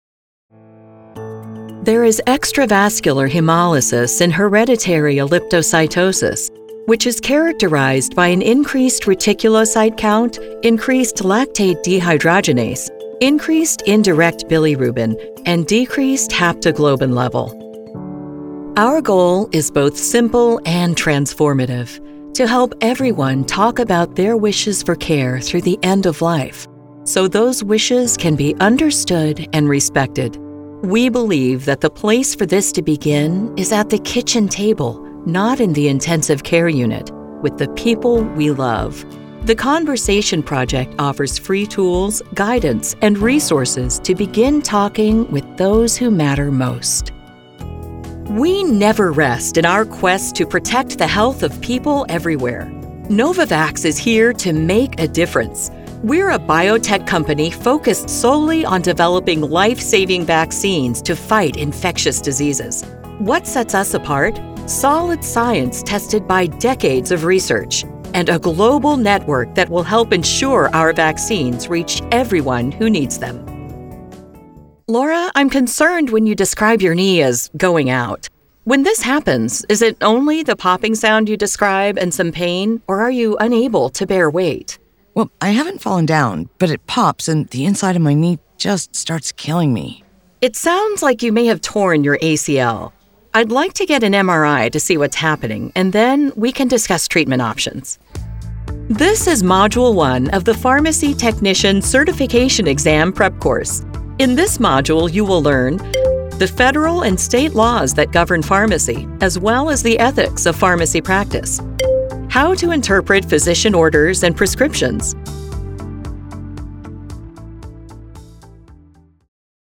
Medical
VO Demos